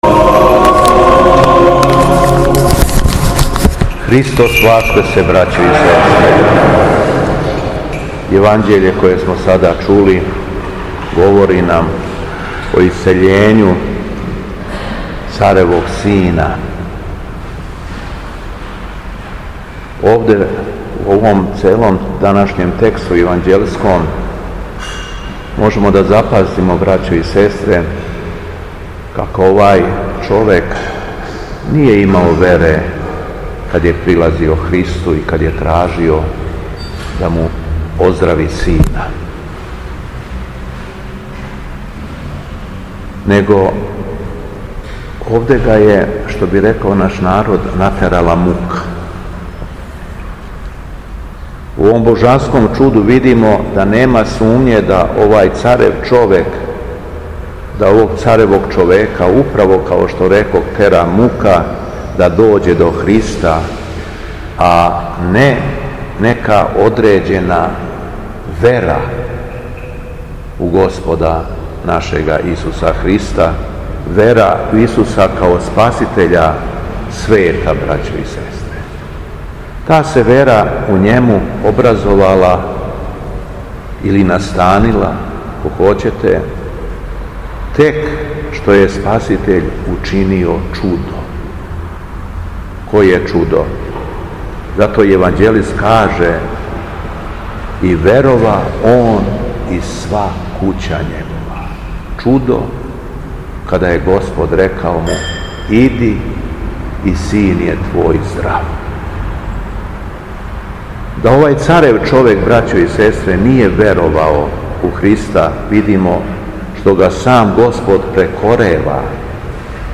Беседа Његовог Високопреосвештенства Митрополита шумадијског г. Јована
После прочитаног јеванђелског зачала Високопреосвећени се обратио верном народу надахнутом беседом: